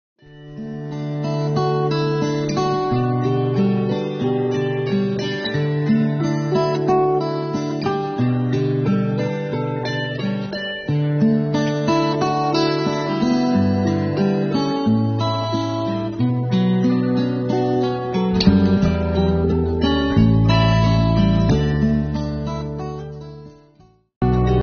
北京市脊髓损伤者希望之家花束编织现场。